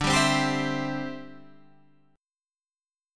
D9sus4 chord